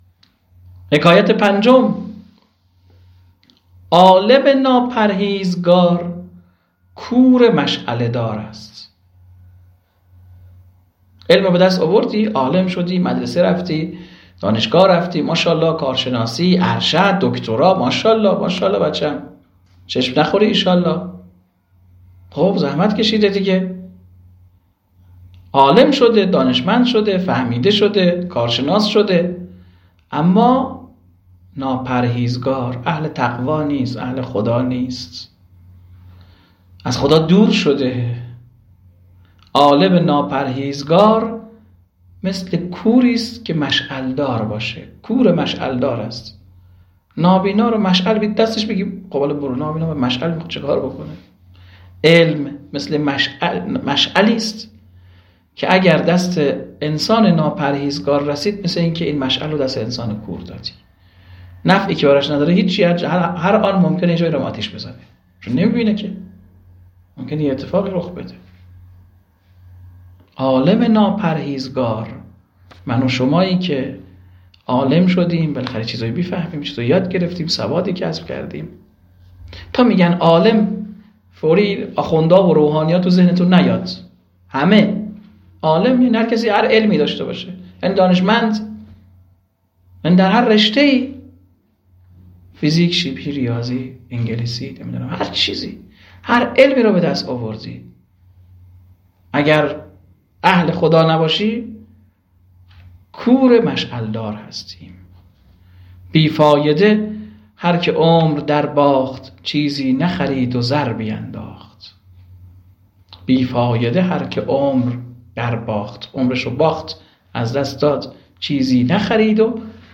آواز و نوا